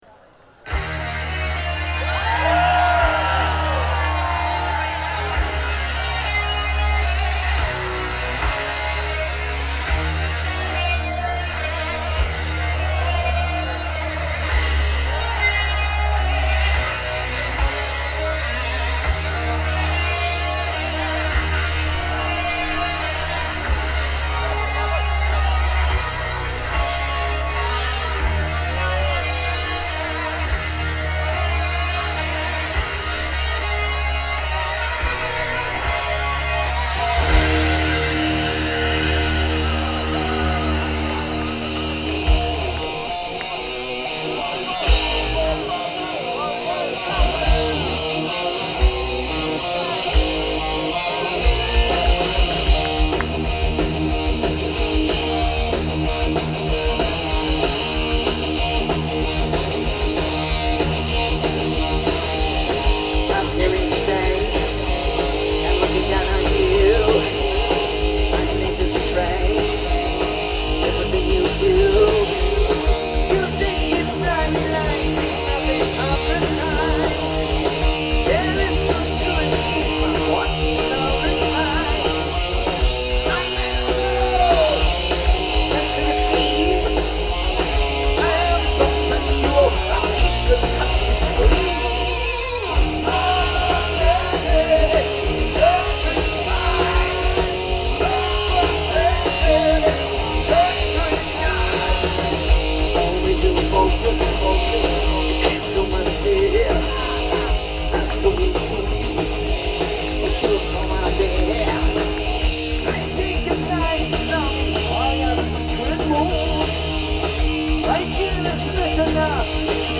quality is very good.